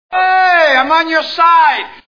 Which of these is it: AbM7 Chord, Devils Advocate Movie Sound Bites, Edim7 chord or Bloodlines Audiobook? Devils Advocate Movie Sound Bites